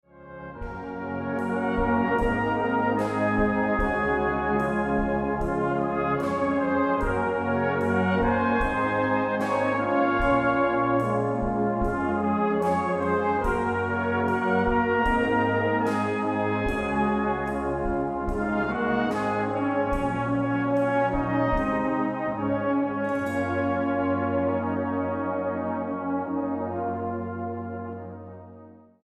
STYLE: Brass Band